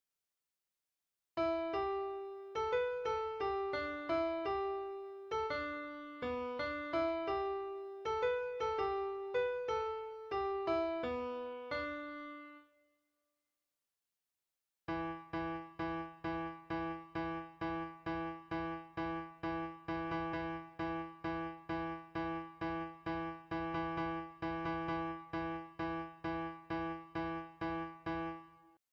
Piano Synthesia Tutorial